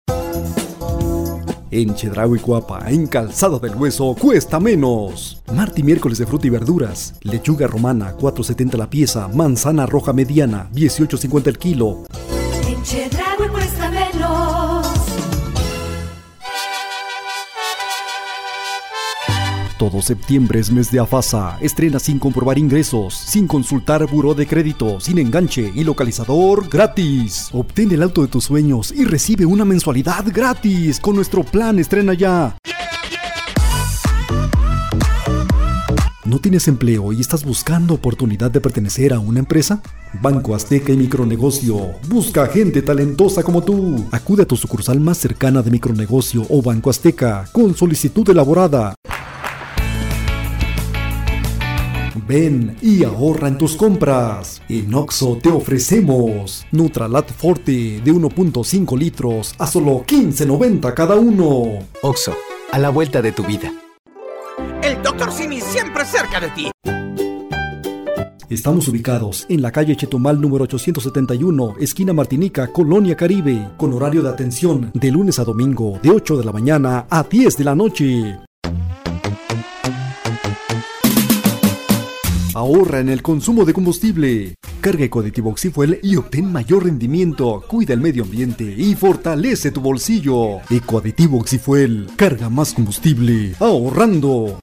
Locutor de radio, conducción de eventos masivos, sociales e institucionales, animador, grabación de spots publicitarios para radio y perifoneo.
Kein Dialekt
Sprechprobe: Werbung (Muttersprache):